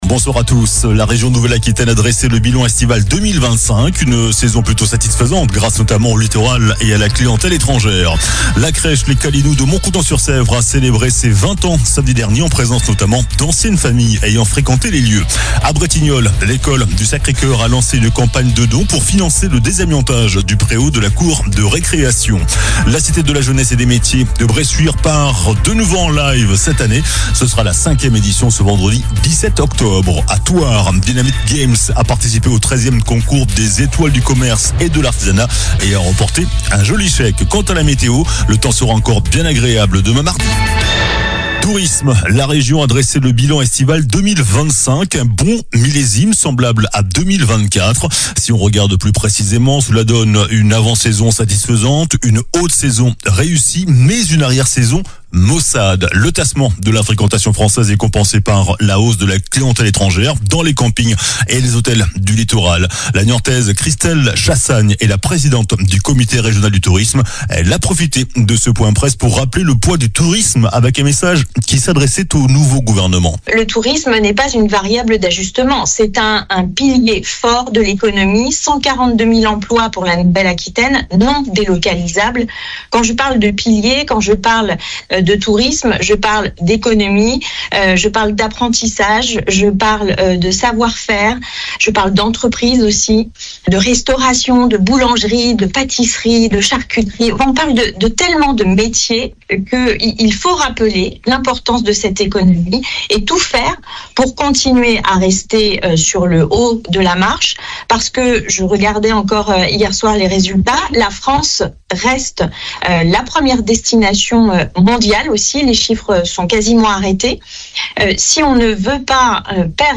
Journal du lundi 13 octobre (soir)